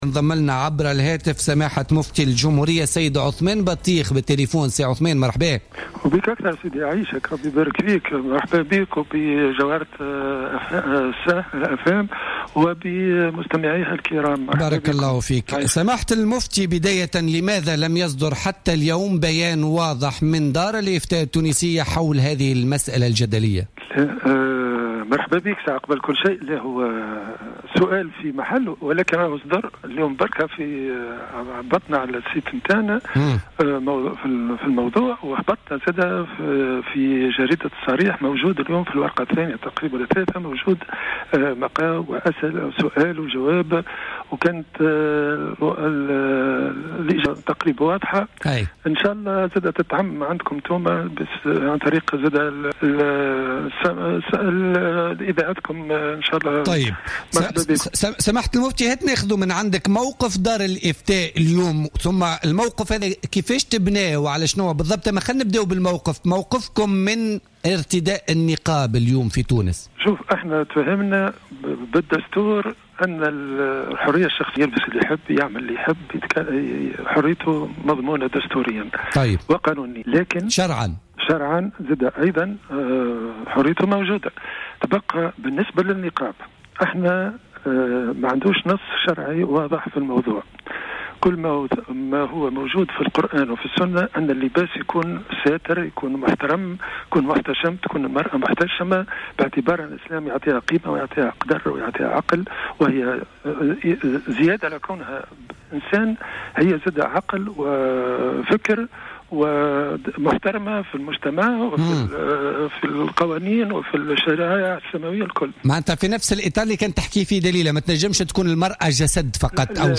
Le Mufti de la République, Othman Battikh s’est prononcé, ce lundi 28 mars 2016, contre le port du niqab, lors d’une intervention dans l’émission Politika sur Jawhara Fm.